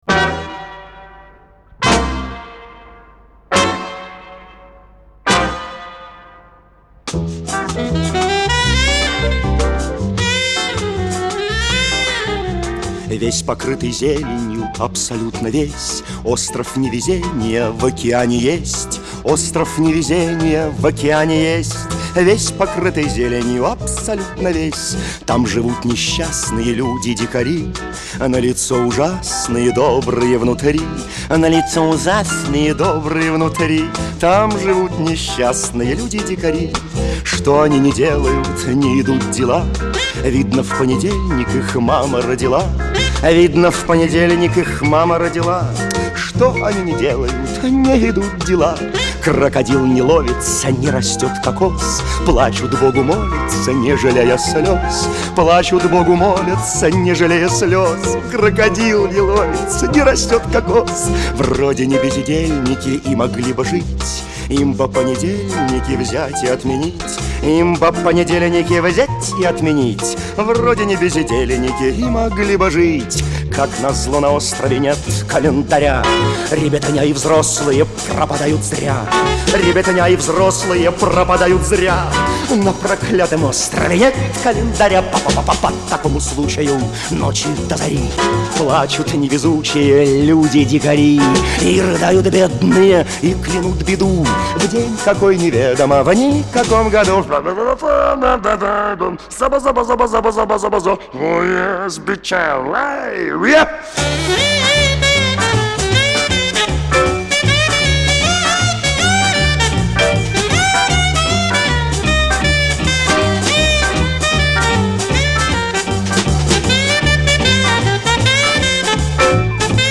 Жанр: Ретро, размер 3.12 Mb.